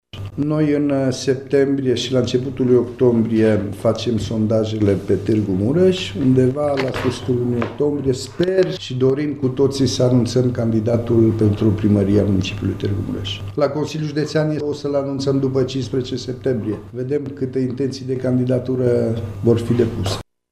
Președintele PNL Mureș, Cristian Chirteș, a declarat ieri, într-o conferință de presă, că aceștia vor fi stabiliți și anunțați în urma unor sondaje interne.